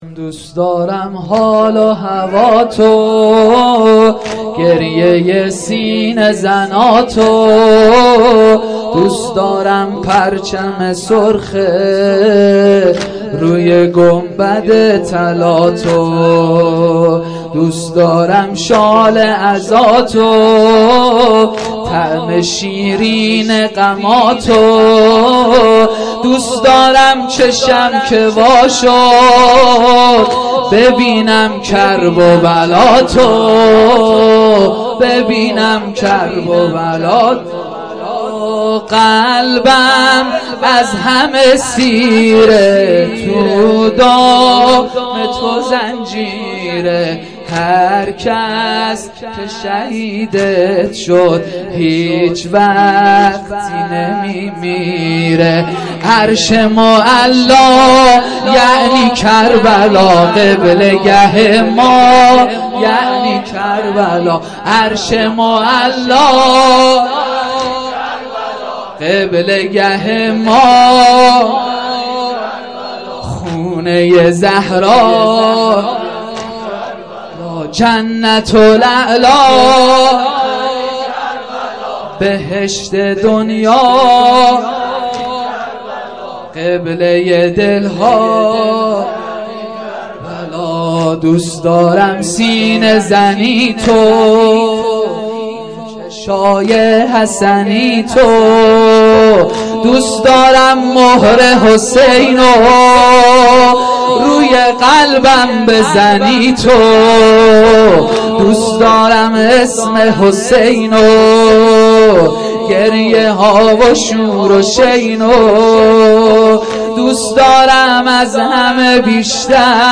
جلسه مذهبی زیارت آل یاسین باغشهر اسلامیه
شب سیزدهم محرم-شام شهادت امام سجاد (ع) 1395